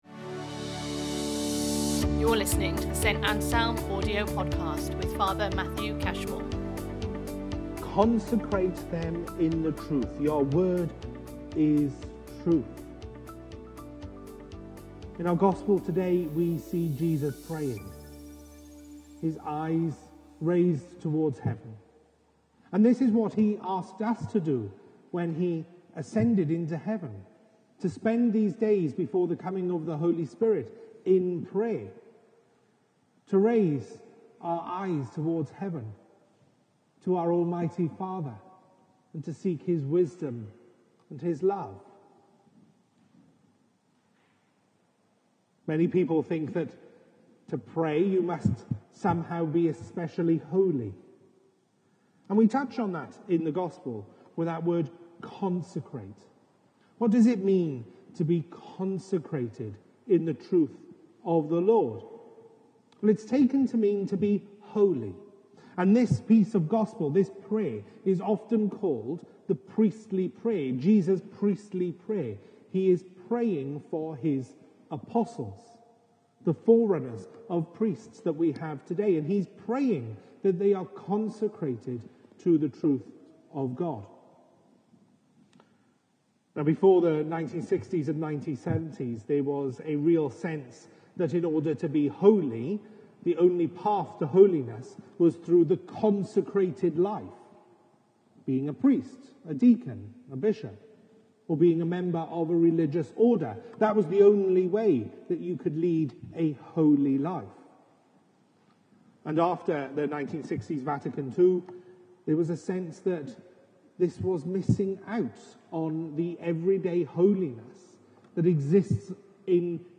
Series Sunday Sermons